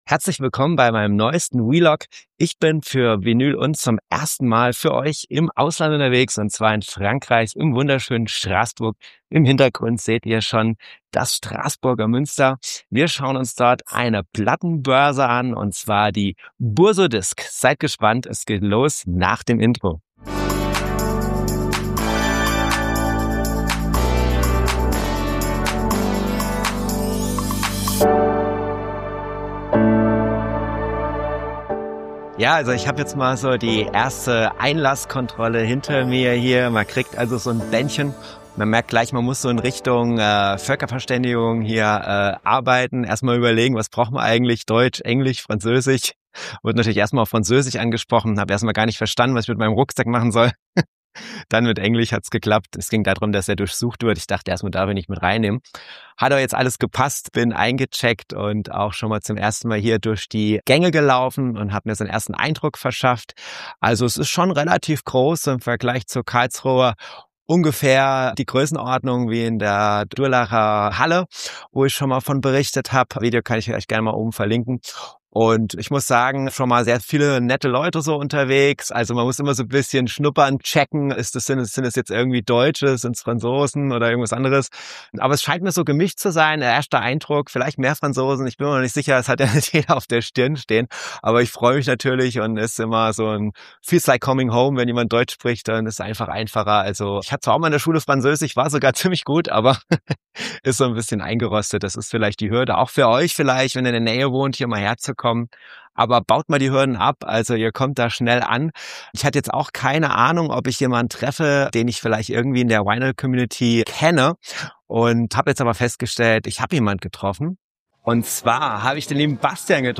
Do., 22.01.2026, ab 20.15 Uhr Herzlich willkommen beim Vinyl & … Vlog von der Bourse aux disques de Strasbourg. Die Plattenbörse zählt zu den Highlights der Region für Plattenfans und hat eine lange Tradition.